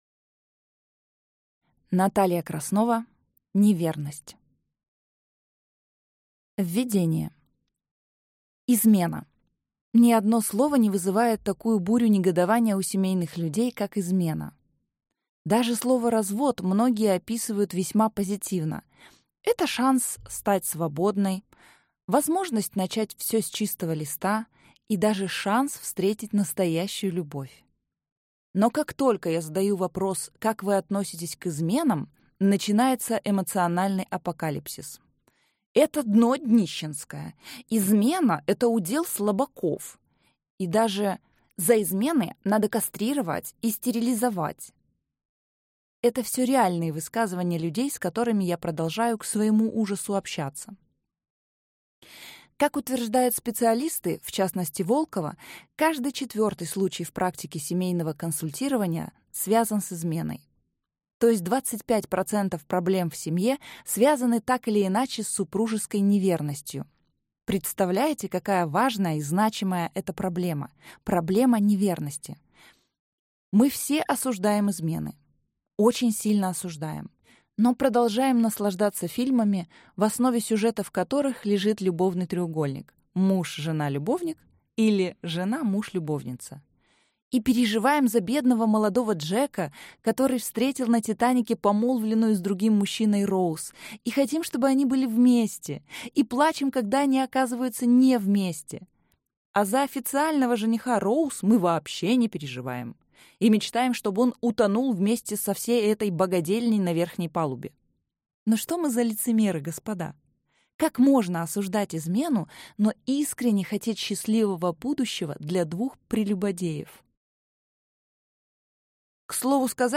Аудиокнига [НЕ]ВЕРНОСТЬ. Что делать, когда не знаешь, что делать | Библиотека аудиокниг